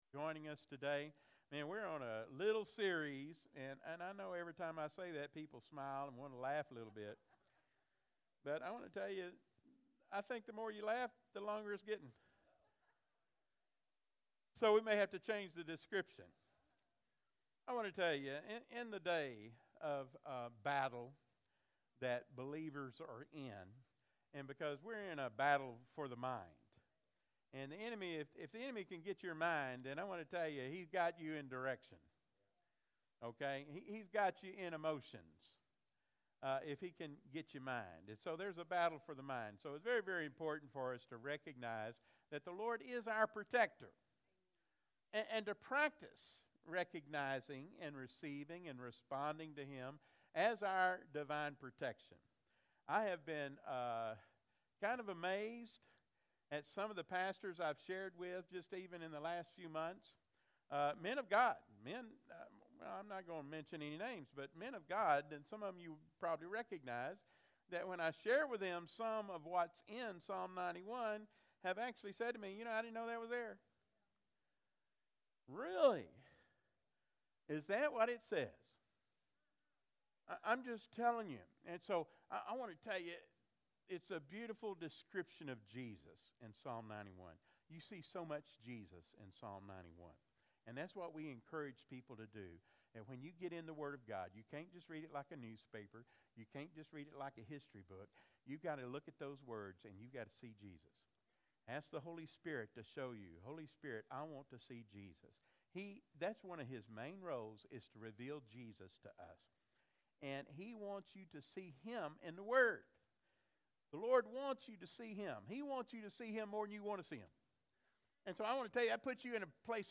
Do not let your past dictate your future with God. Freedom Life Fellowship Euless, TX / Freedom Life Fellowship Live Stream